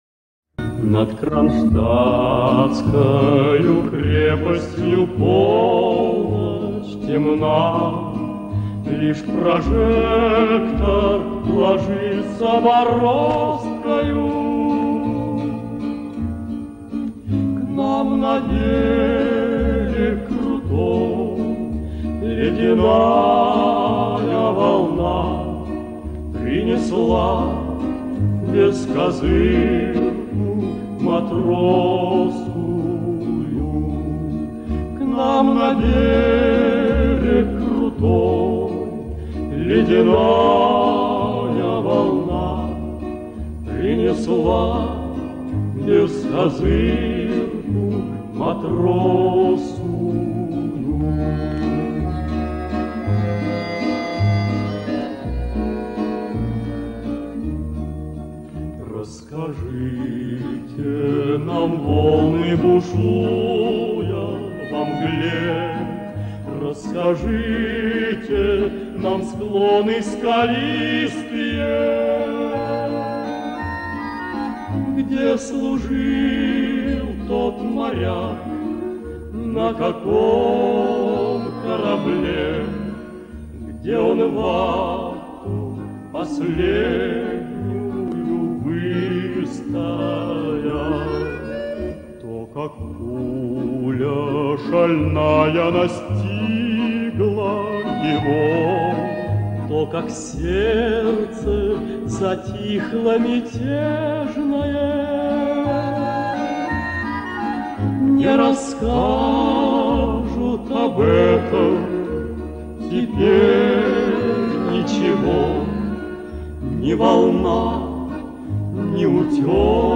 Источник видеофайл из ВК, вытянул аудио